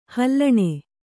♪ hallaṇe